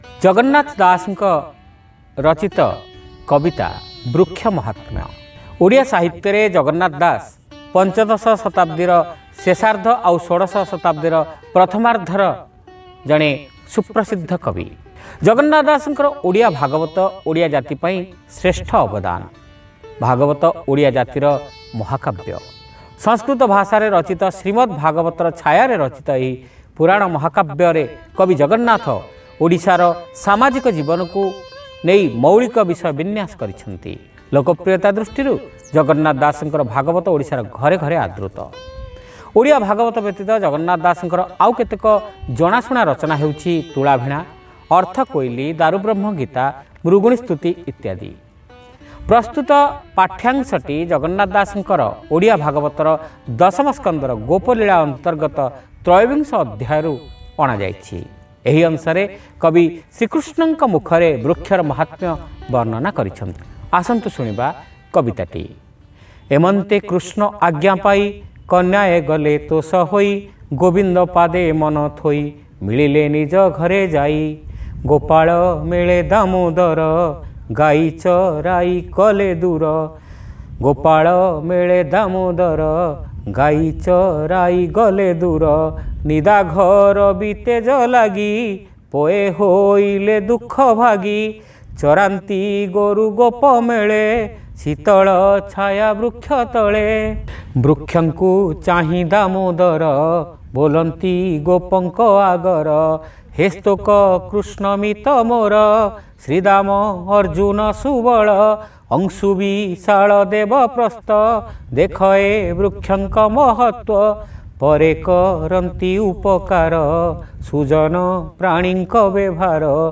ଶ୍ରାବ୍ୟ କବିତା : ବୃକ୍ଷ ମାହାତ୍ମ୍ୟ